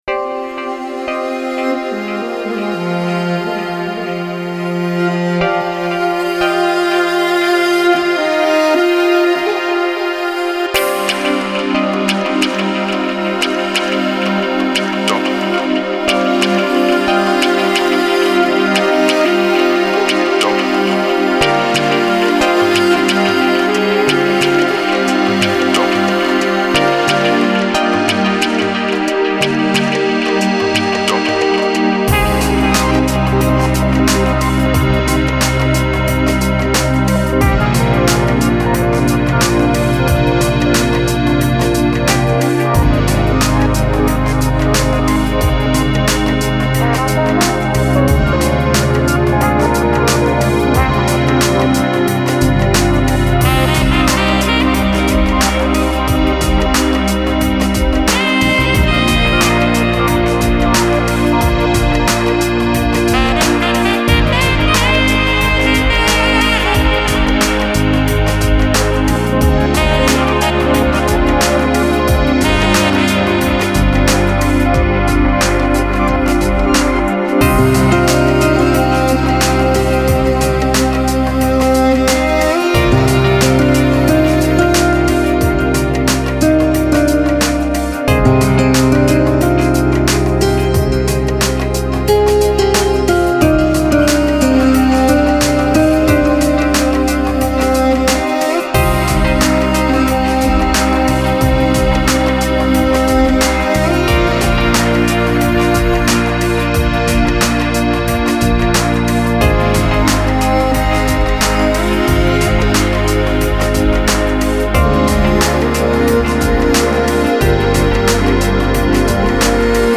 Genre: Chillout, Lounge, Downtempo.